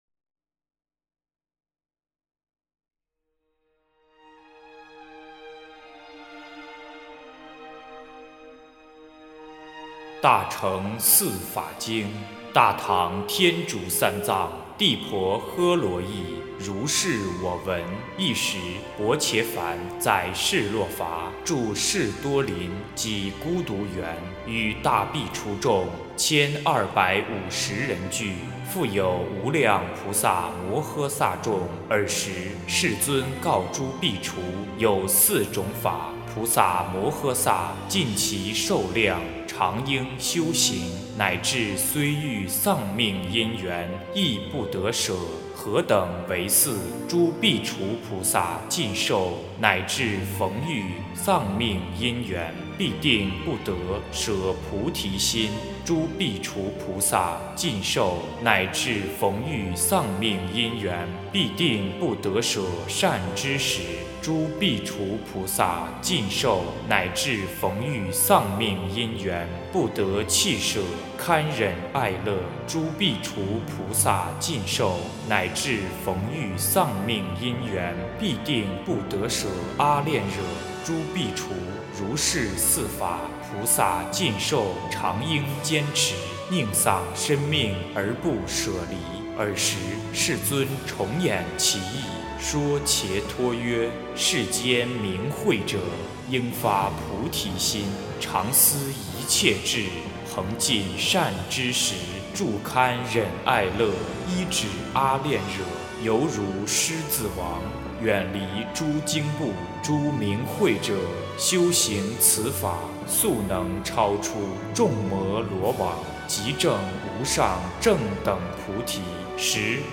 诵经
佛音 诵经 佛教音乐 返回列表 上一篇： 心地法门-道信大师词 下一篇： 遵佛令 相关文章 法华经-常不轻菩萨品第二十 法华经-常不轻菩萨品第二十--未知...